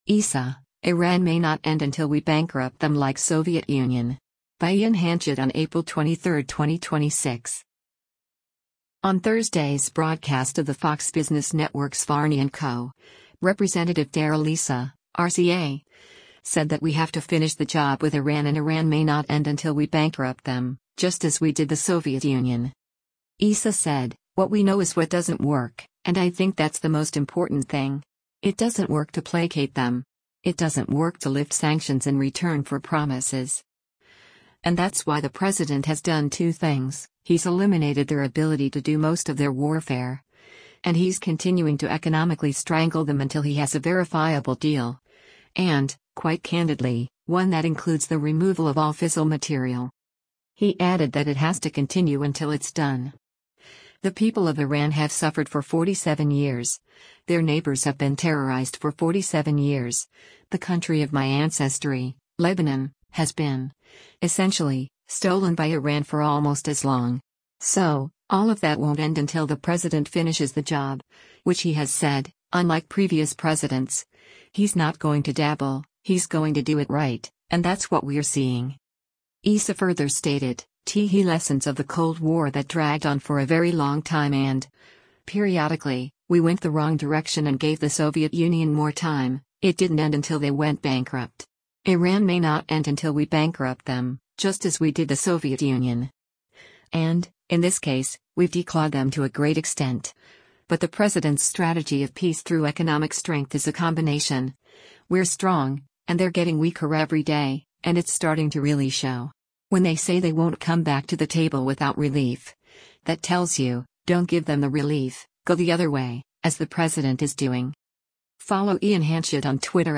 On Thursday’s broadcast of the Fox Business Network’s “Varney & Co.,” Rep. Darrell Issa (R-CA) said that we have to finish the job with Iran and “Iran may not end until we bankrupt them, just as we did the Soviet Union.”